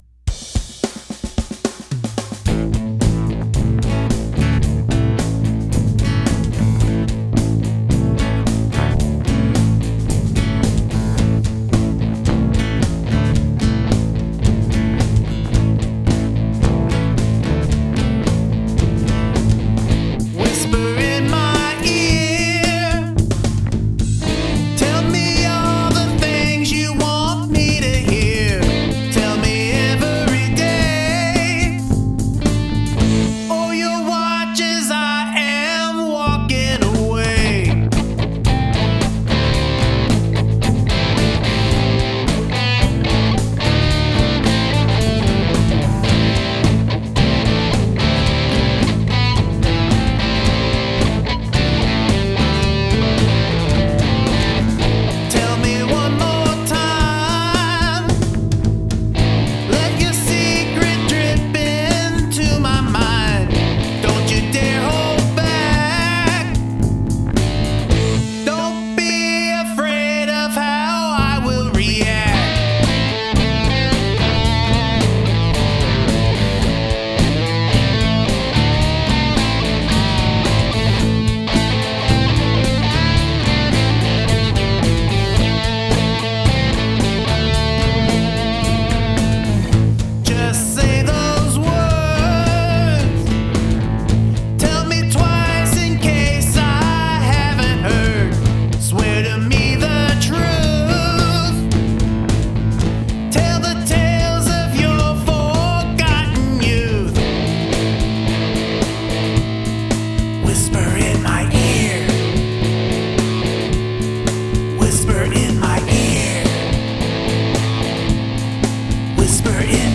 Brilliant, hard to believe this is a one chord song.
Loved the crunchy guitar tone - a bit ZZ Top sound, imo!